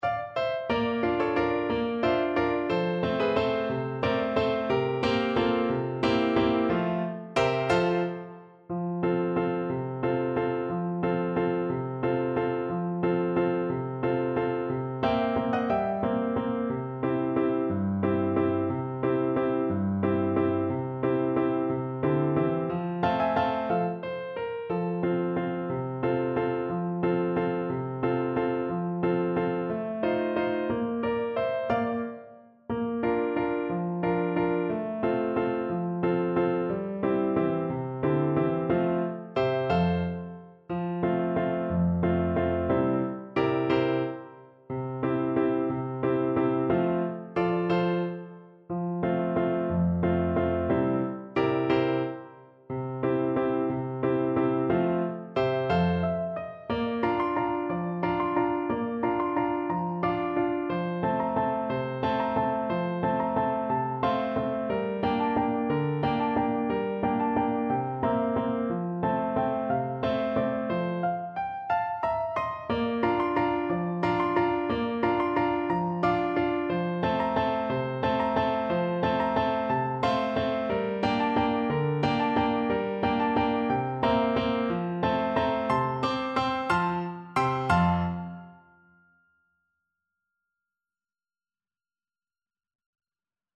French Horn version
French Horn
Traditional Music of unknown author.
3/4 (View more 3/4 Music)
F major (Sounding Pitch) C major (French Horn in F) (View more F major Music for French Horn )
One in a bar .=c.60
Classical (View more Classical French Horn Music)